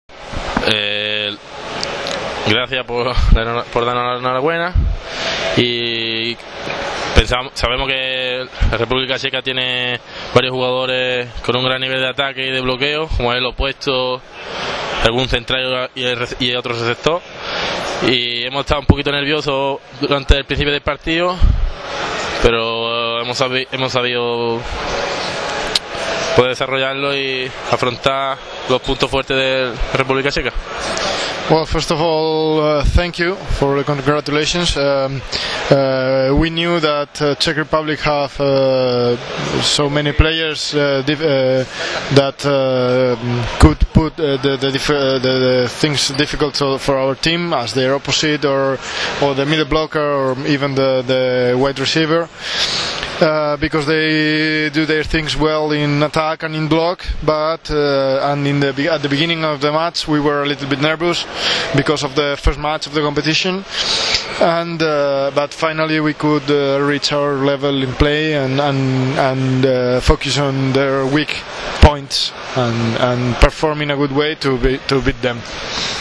IZJAVA
SA PREVODOM